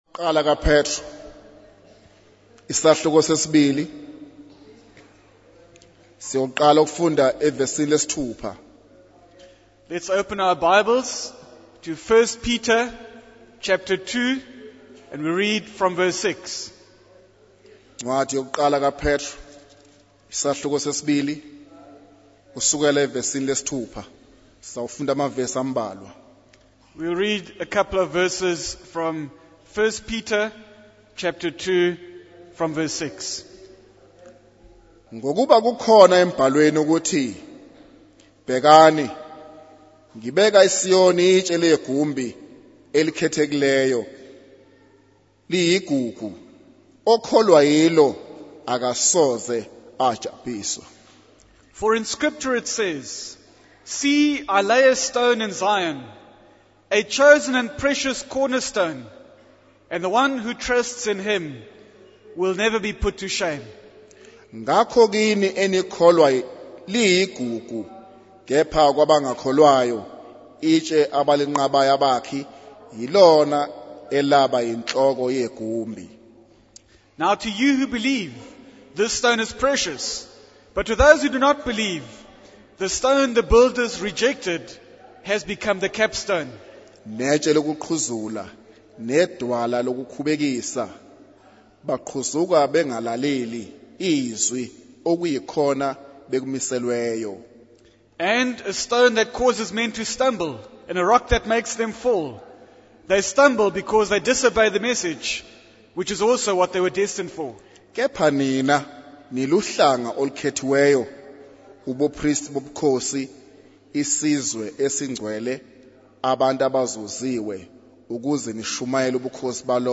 In this sermon, the preacher urges the congregation to abstain from sinful desires and live good lives among non-believers. The preacher emphasizes the importance of Jesus Christ as the foundation and cornerstone of the church.